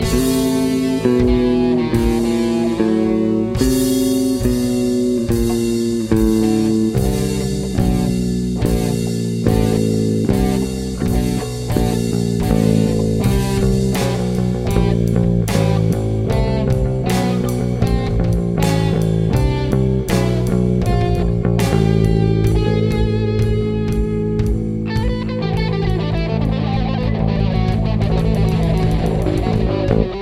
The album is essentially entirely improvised.
It is awfully noodly.